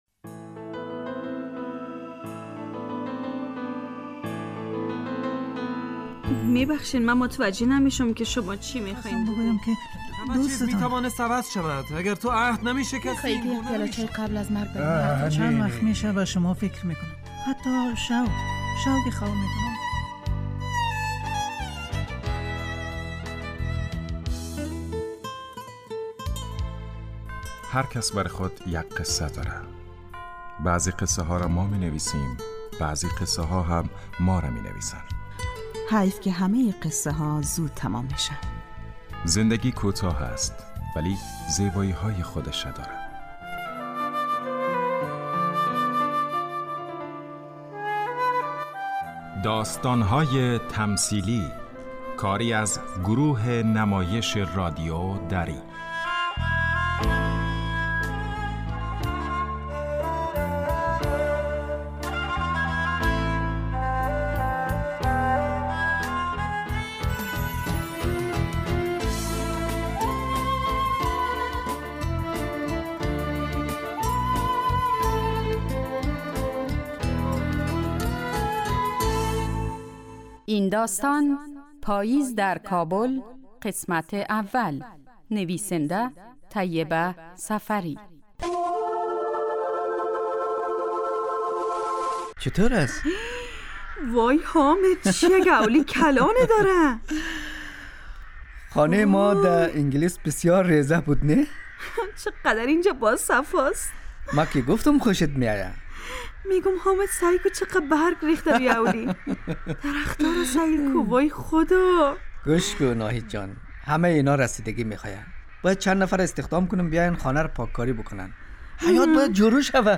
داستانهای تمثیلی نمایش 15 دقیقه ای هستند که روزهای دوشنبه تا پنج شنبه ساعت 03:25عصربه وقت وافغانستان پخش می شود.